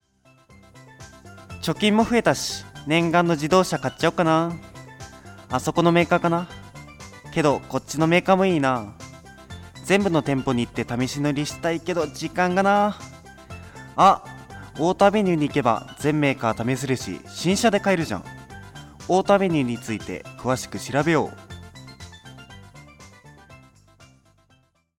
FM西東京の番組「ミッドナイトスクール」内でオンエアされています。